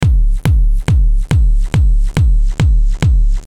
Kick-drum-loop-140-bpm-techno-electronic.mp3